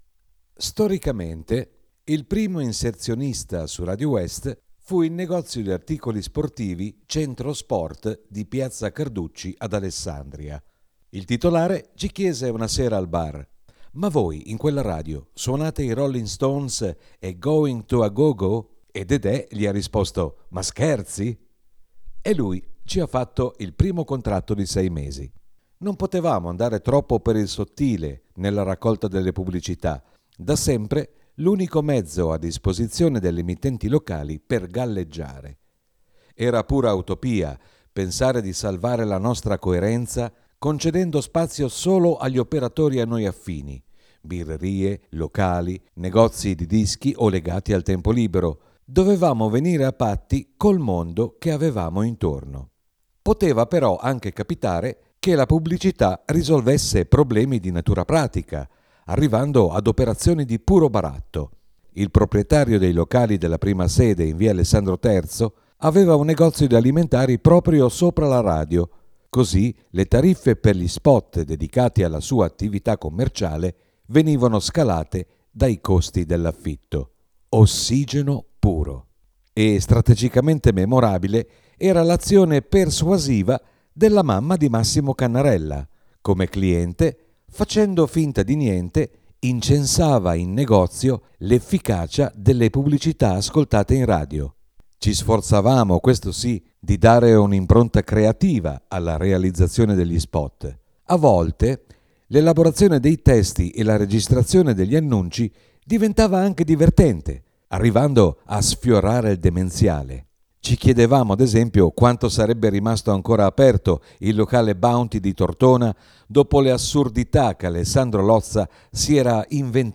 Estratto dal libro “Alza il volume” (LineLab edizioni, 2018).